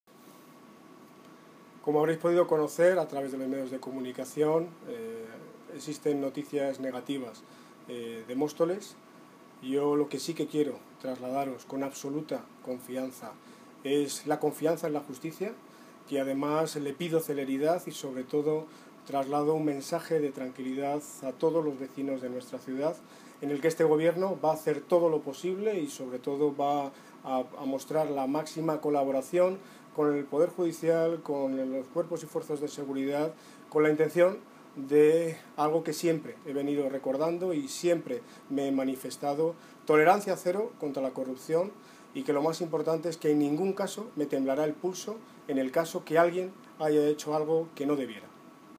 Audio de Daniel Ortiz, Alcalde de Móstoles